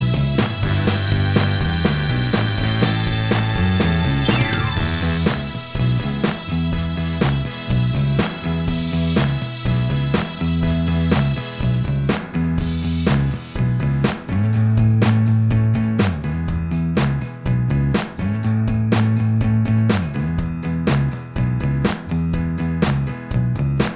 Minus Electric Guitar Glam Rock 4:45 Buy £1.50